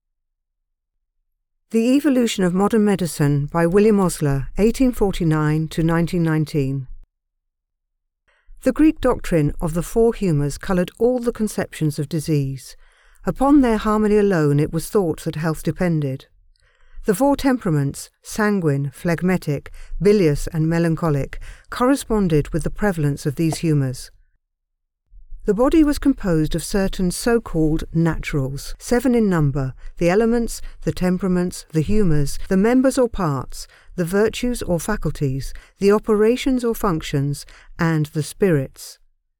I have an RP voice capable of many diverse pitches and accents. A compassionate voice.